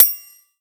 Metal Clank 4
clang clank ding hit impact metal metallic ping sound effect free sound royalty free Sound Effects